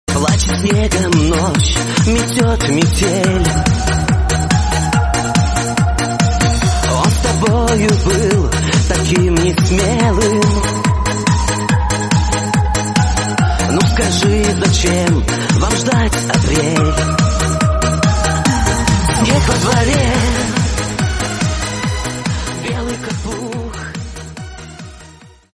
мужской голос
танцевальная музыка